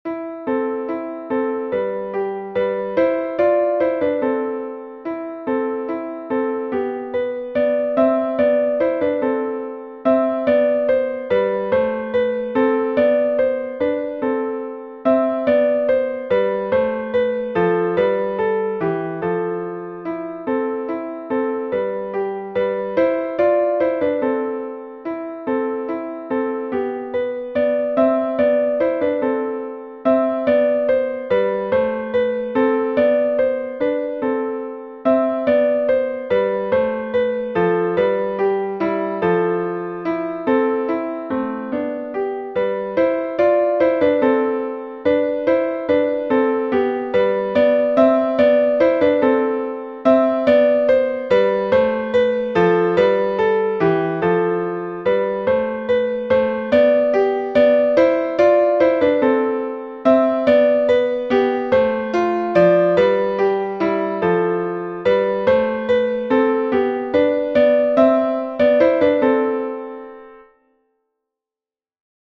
easy and engaging piano solos
Instructional, Medieval and Renaissance